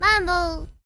manbo Meme Sound Effect